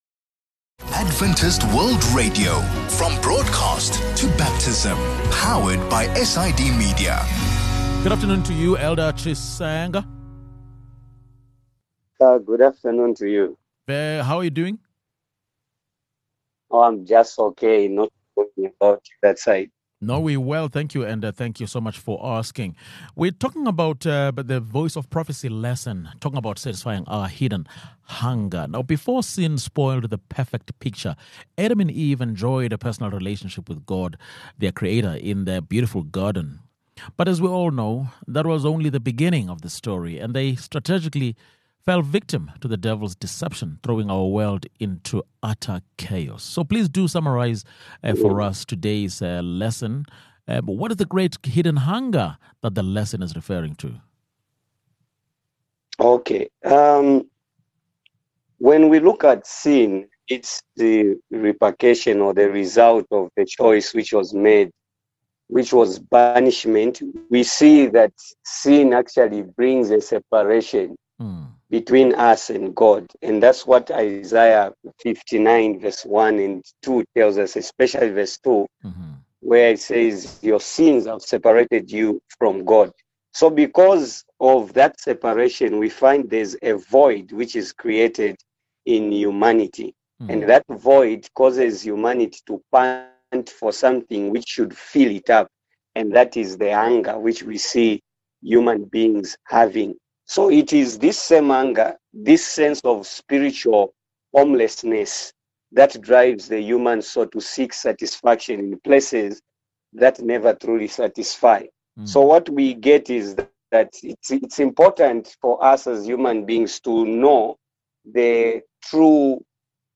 A lesson on the God-shaped hunger built into every human being, and how we can find satisfaction for it.